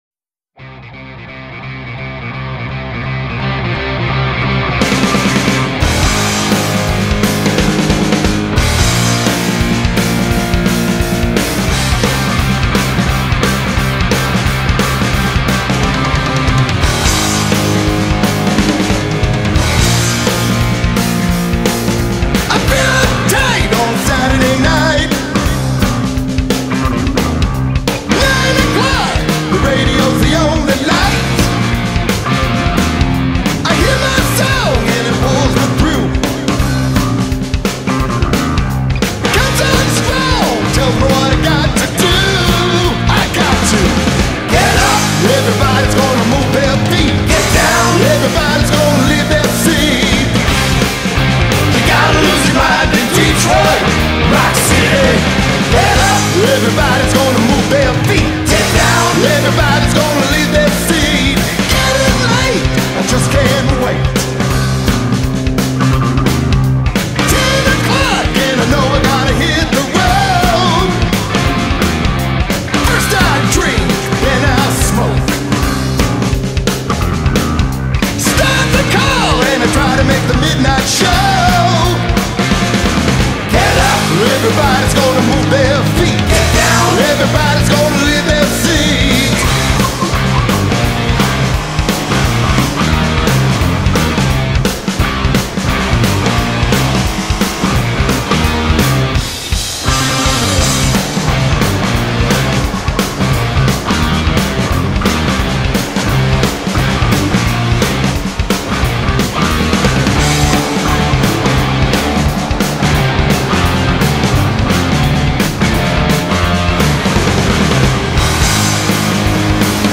Hard Rock, Heavy Metal